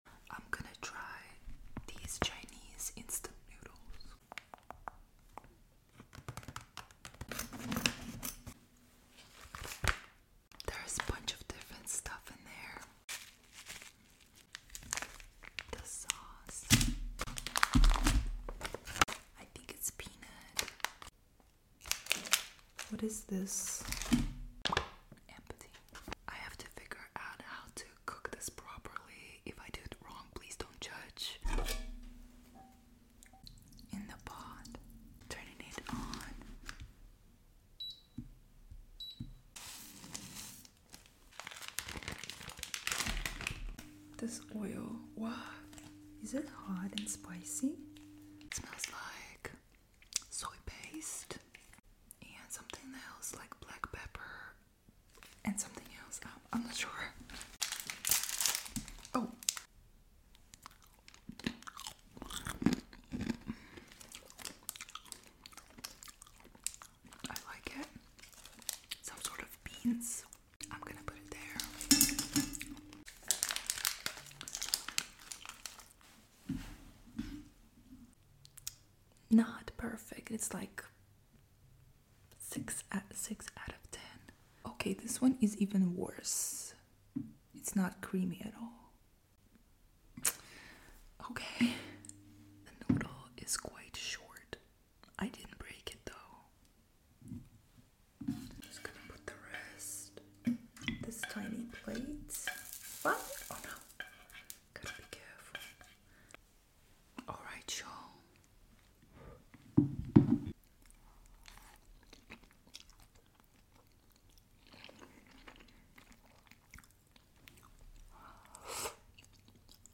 ASMR trying Chinese instant noodles sound effects free download